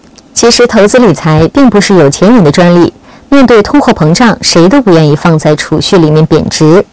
新闻-噪音1.wav